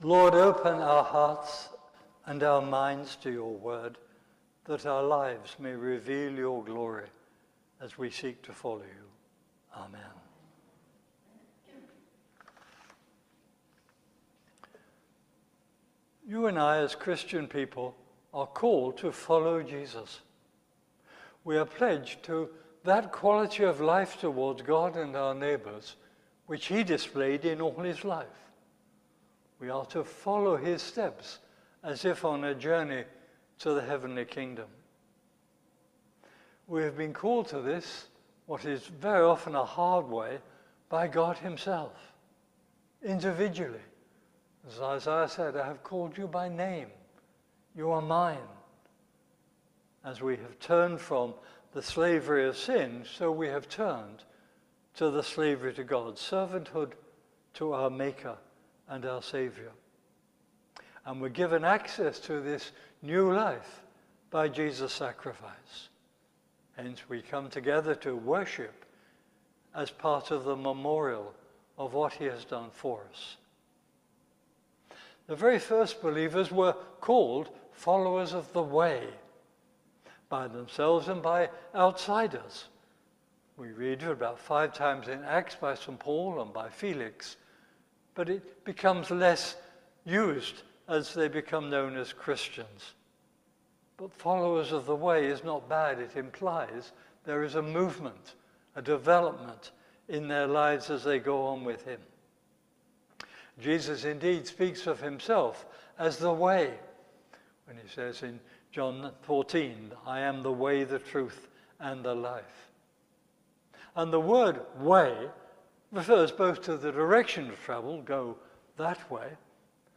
Media for Holy Communion on Sun 29th Jun 2025 09:00 Speaker
Theme: We are Gods messengers Sermon Search